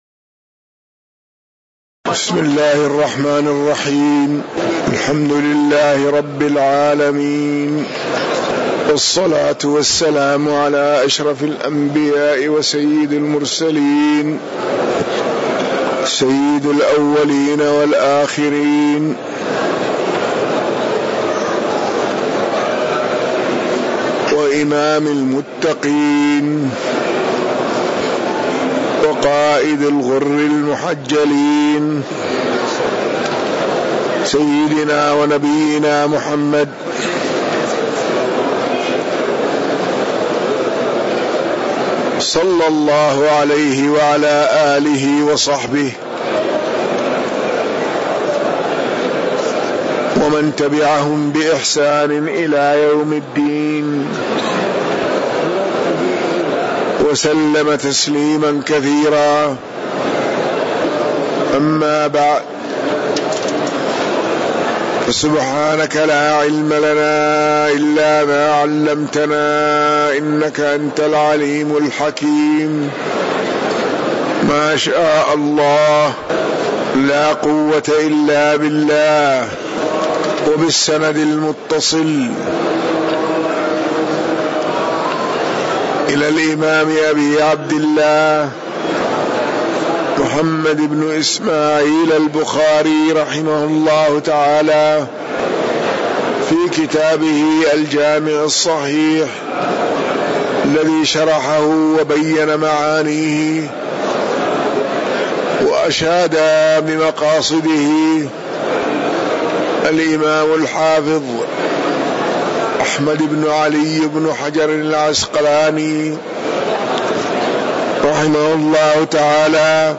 تاريخ النشر ١٥ جمادى الآخرة ١٤٤٠ هـ المكان: المسجد النبوي الشيخ